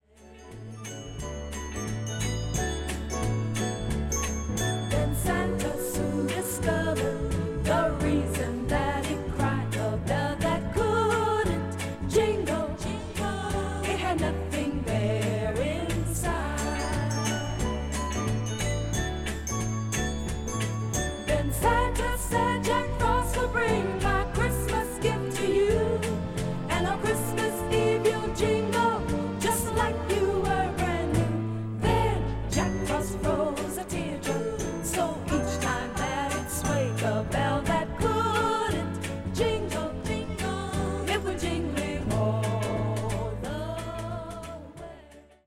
ハッピーでスウィートなウィンター・ソングが詰まっています。